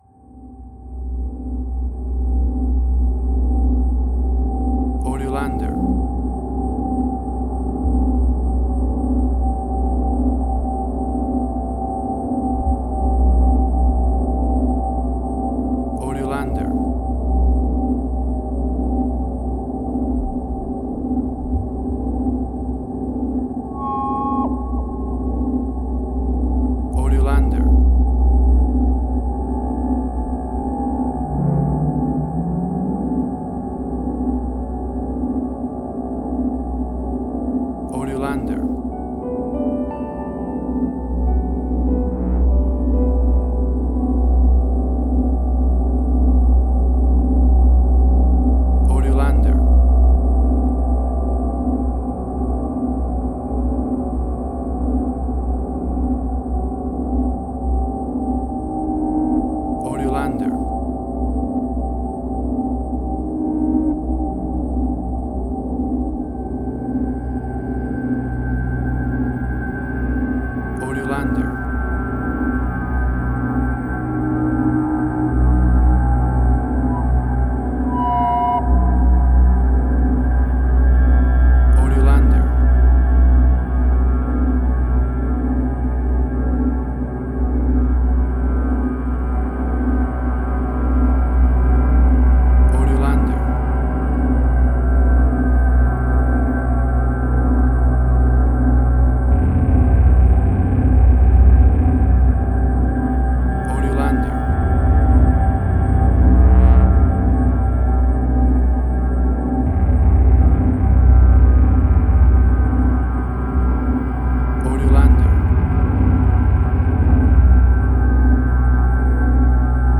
Post-Electronic.
Tempo (BPM): 116